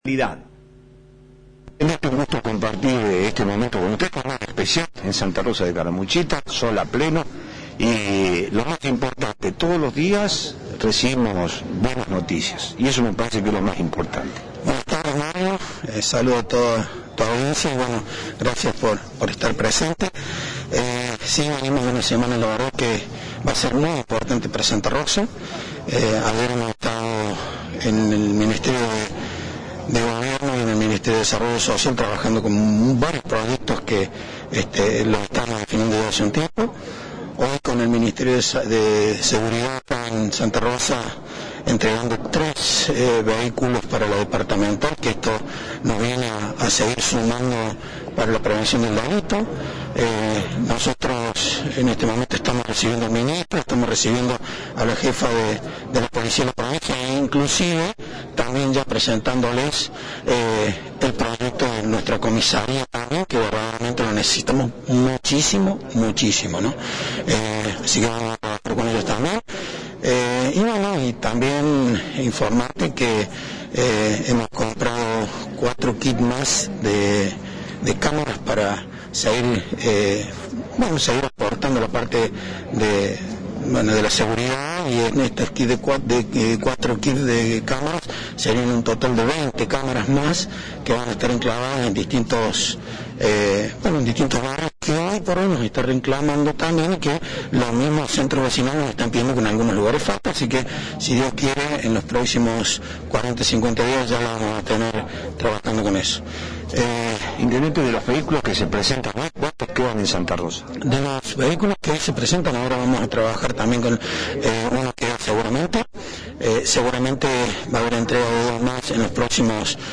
En el marco de la visita del Ministro de seguridad de Córdoba, el intendente de santa Rosa realizó importantes anuncios en diálogo con Flash FM.